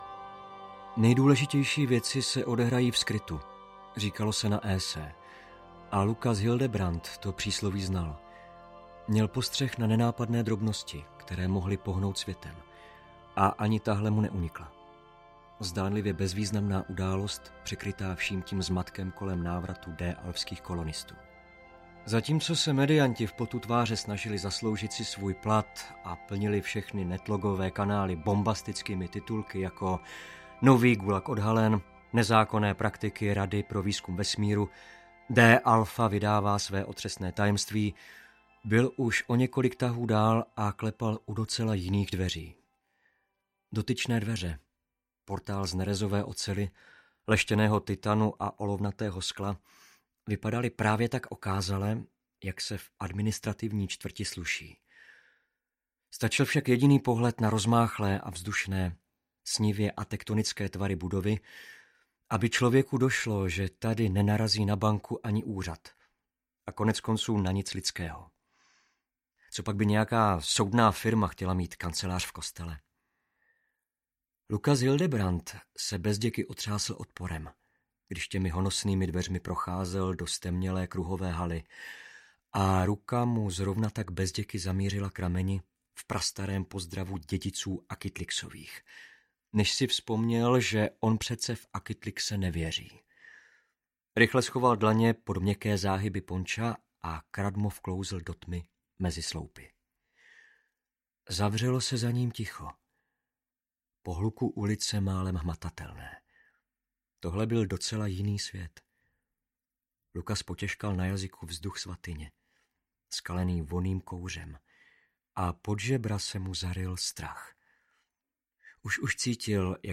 Velkolepá audio adaptace nejlepšího českého sci-fi románu loňského roku v podání devíti pečlivě vybraných interpretů, které zprostředkuje literární zážitek lépe než dalekozření i osvícení dohromady.
Ukázka z knihy